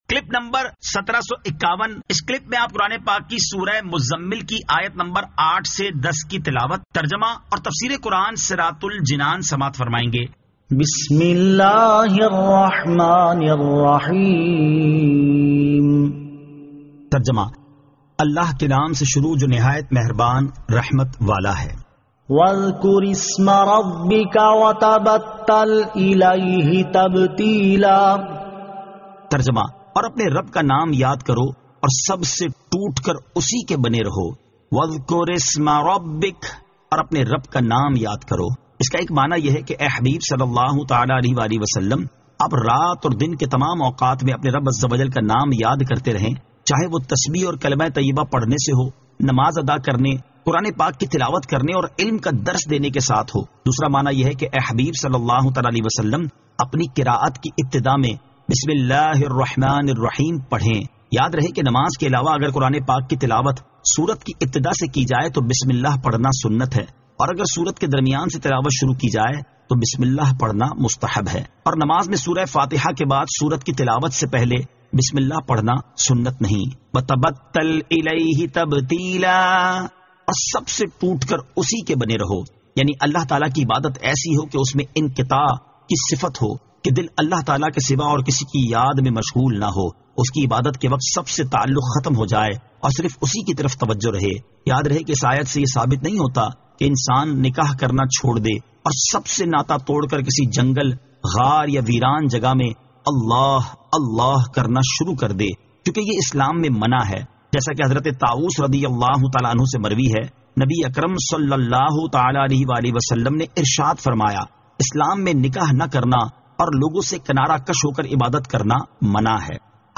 Surah Al-Muzzammil 08 To 10 Tilawat , Tarjama , Tafseer